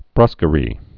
(brüskə-rē)